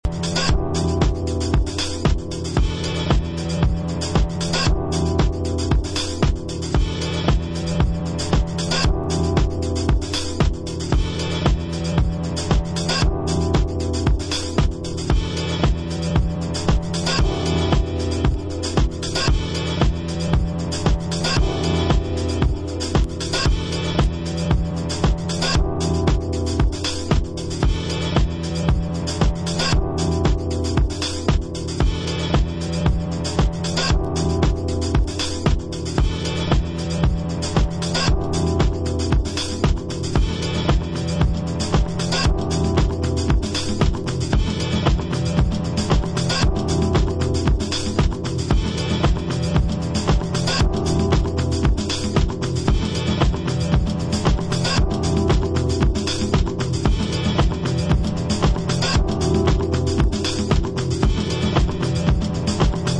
All In all, it’s House!